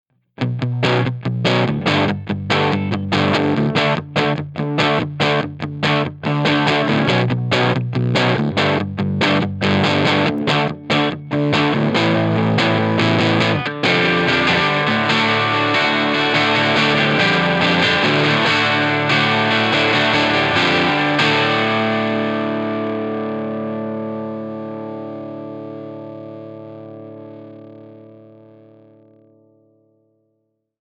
JTM45 Dirty Fender Oxford
What makes this clip difficult is some speakers work well with the PM's in the first half while others handle the open chord work much better.
JTM_DIRTY_FenderOxfords.mp3